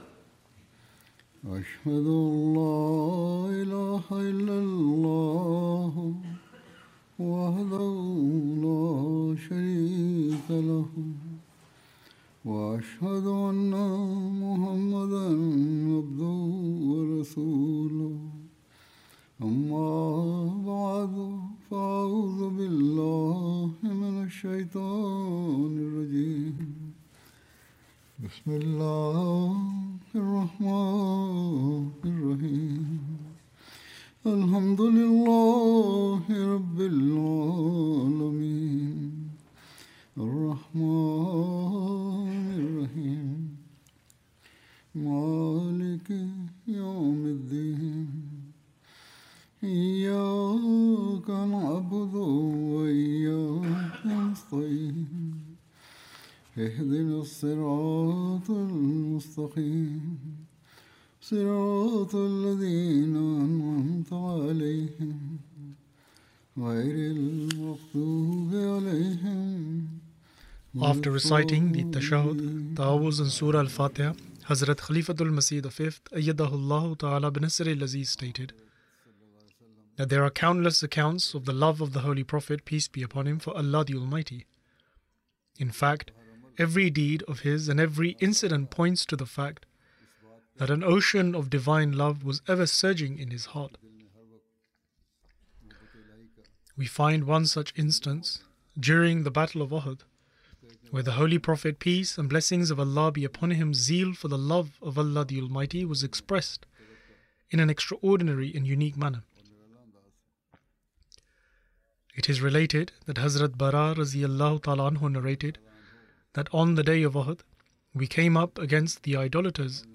English translation of Friday Sermon (audio)